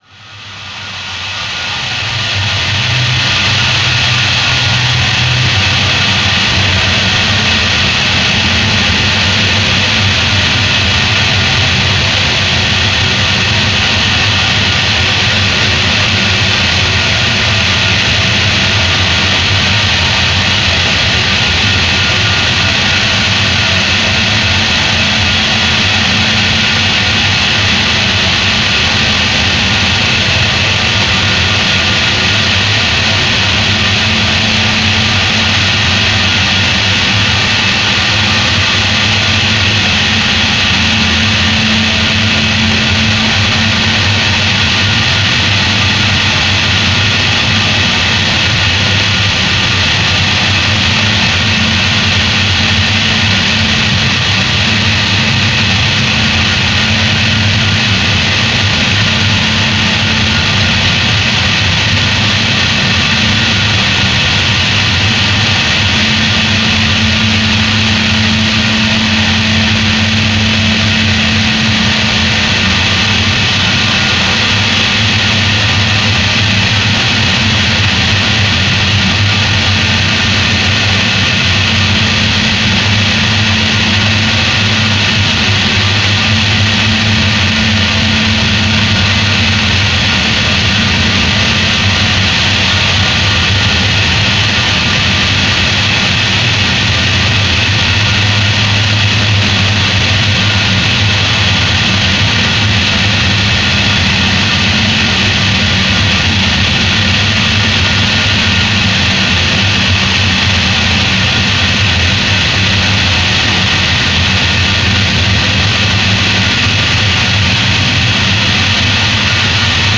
harsh noise project